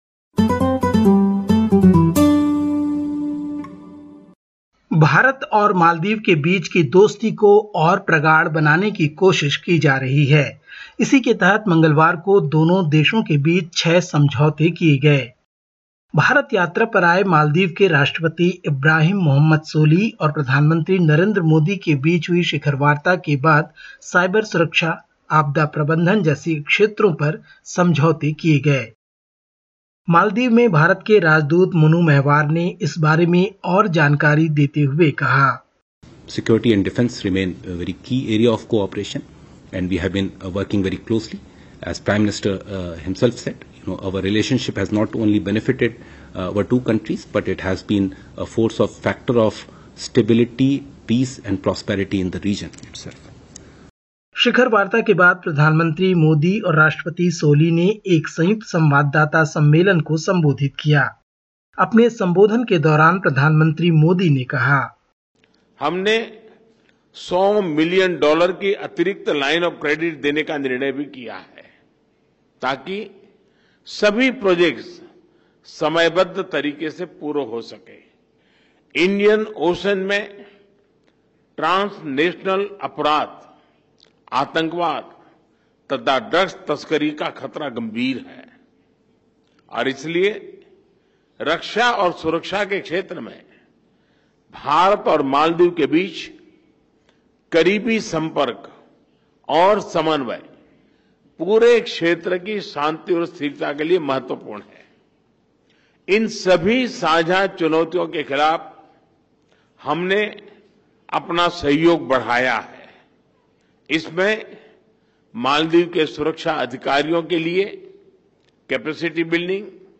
Listen to the latest SBS Hindi report from India. 08/08/2022